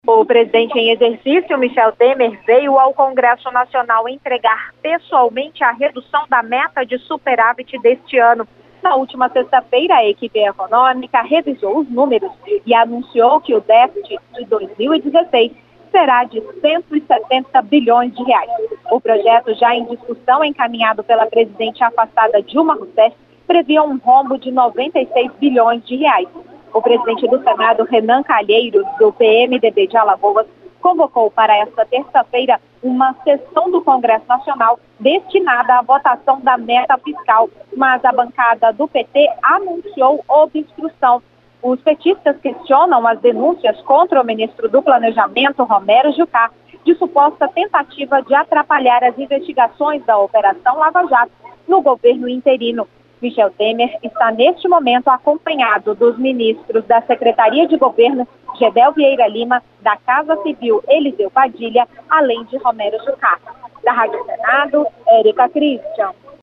As informações com a repórter